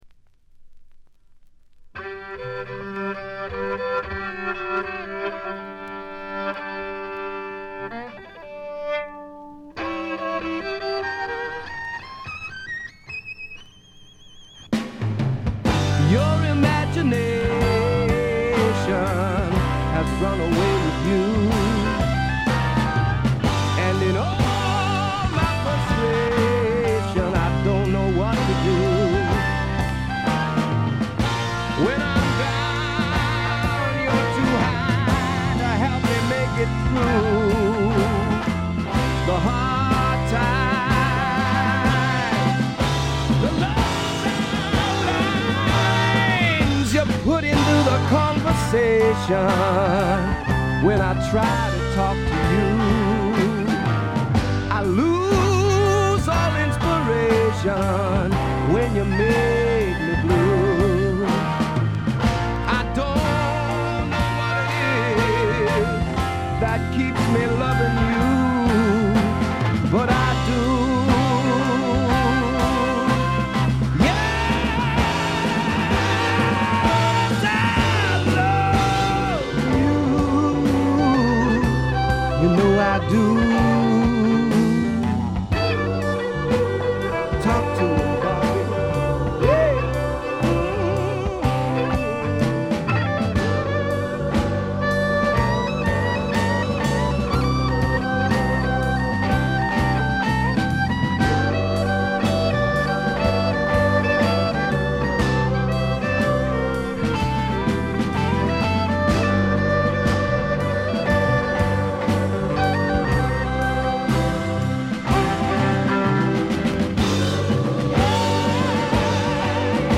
69年という最良の時代の最良のブルース・ロックを聴かせます。
試聴曲は現品からの取り込み音源です。